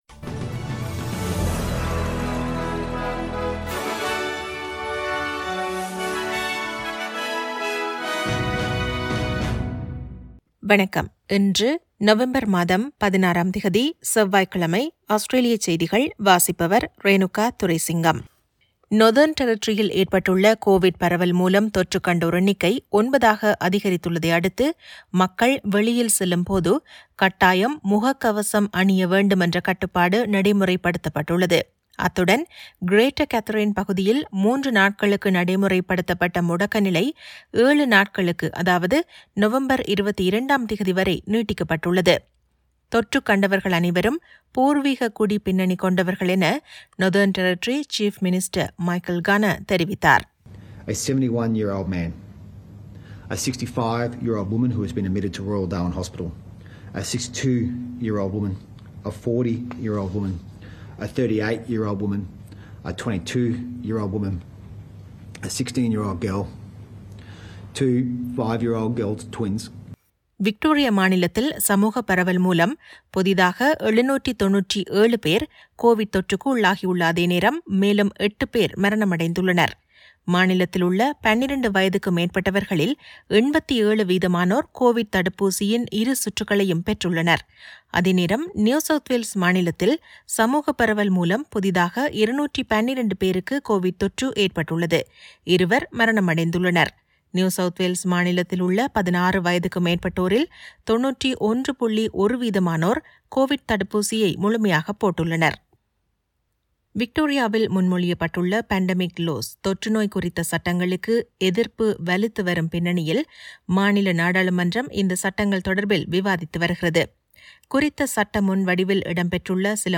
Australian news bulletin for Tuesday 16 Nov 2021.